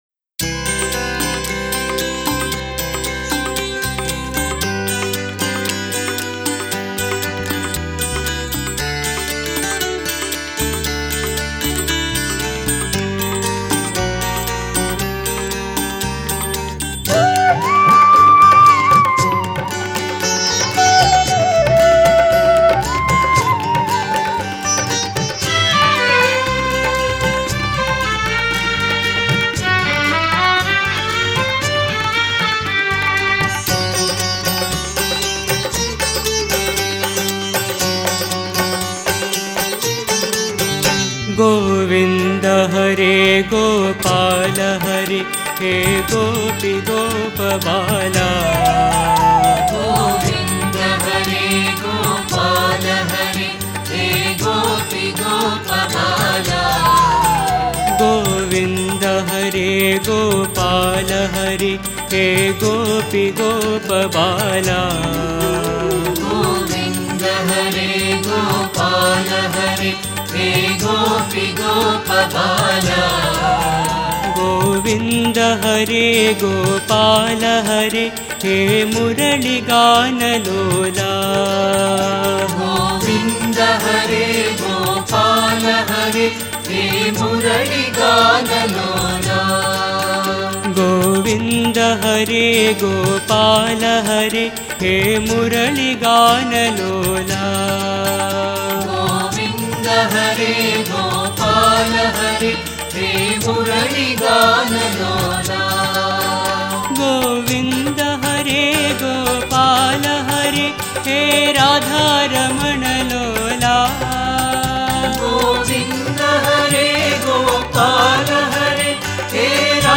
Author adminPosted on Categories Krishna Bhajans